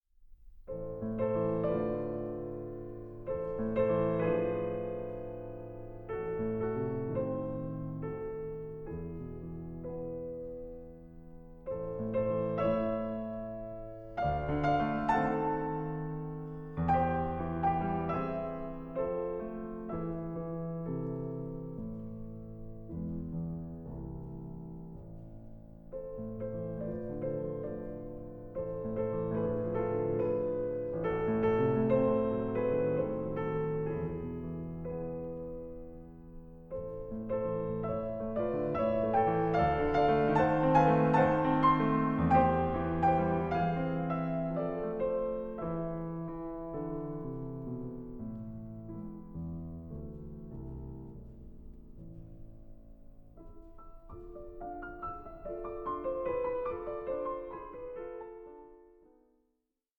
piano
By turns placid, sparse, restive and impassioned